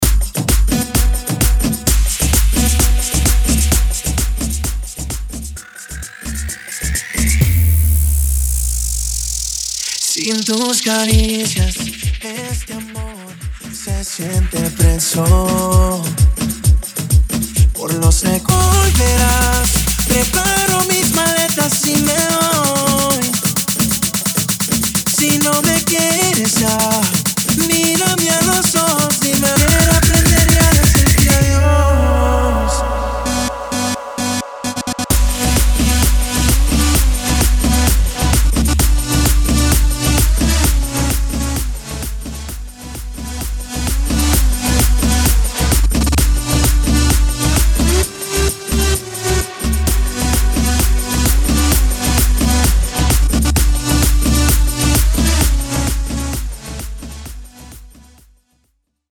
dj remixes
BPM 130 / Guaracha